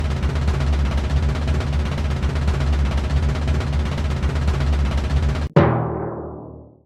10. Дробь и в конце заключительный удар